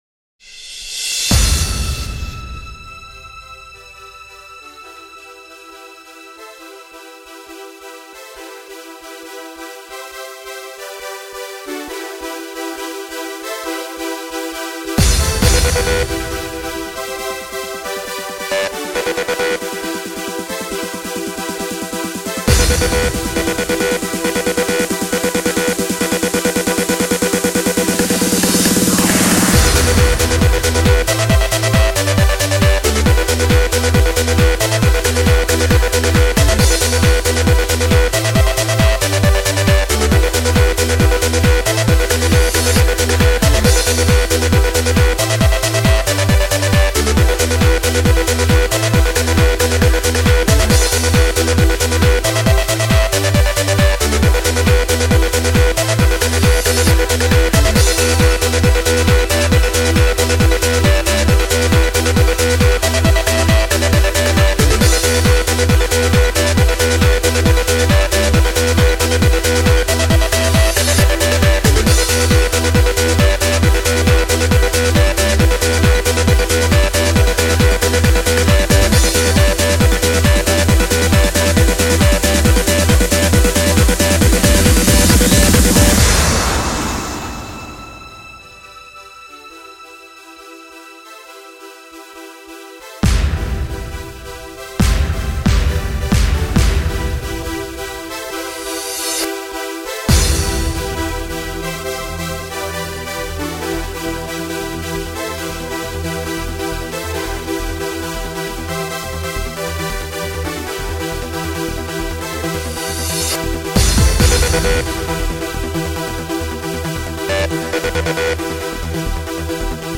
EDM 2000er